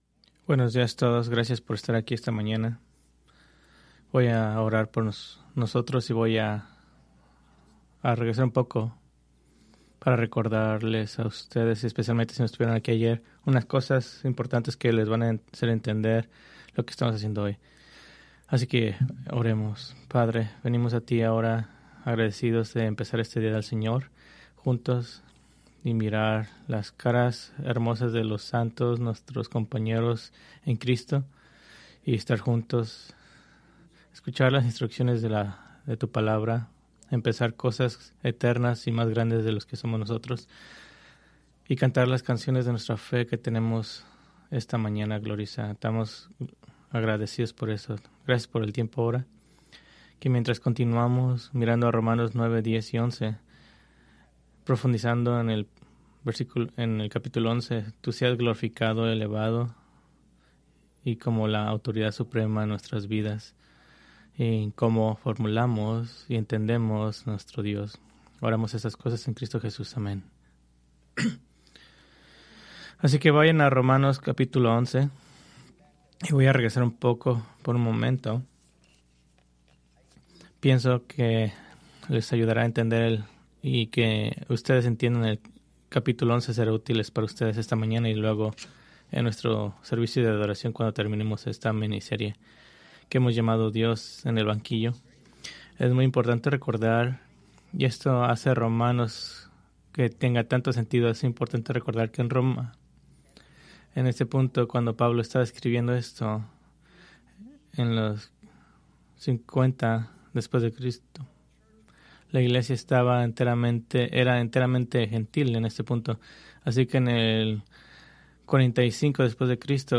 Preached April 19, 2026 from Romanos 11:1-24